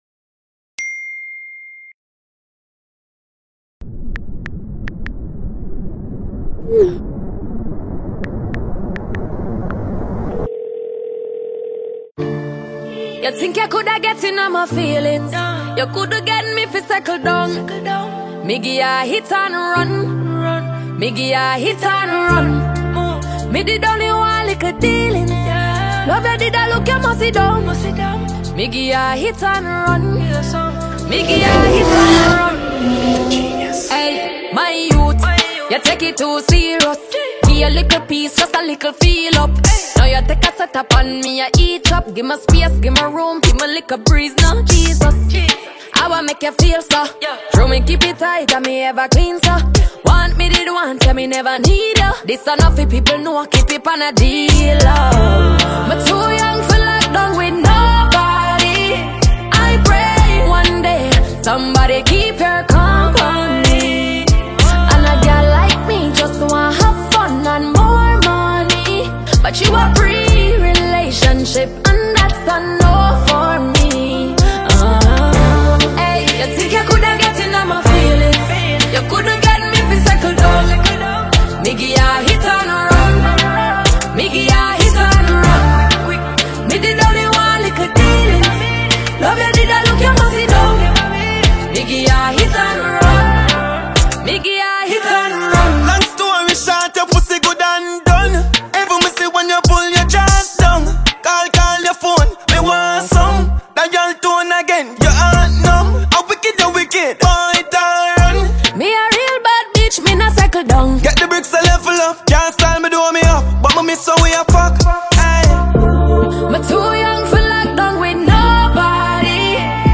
Jamaican dancehall